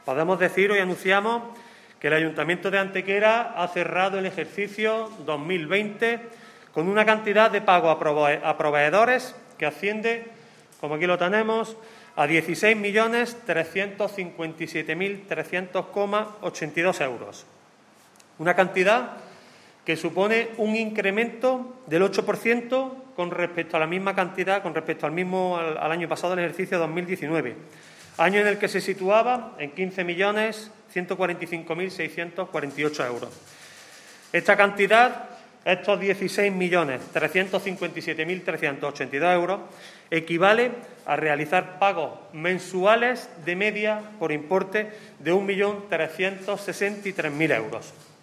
El teniente de alcalde delegado de Hacienda, Antonio García, ha comparecido en la mañana de hoy ante los medios de comunicación para resumir la actividad económica del Ayuntamiento de Antequera durante el pasado año 2020 en lo que a pagos se refiere.
Cortes de voz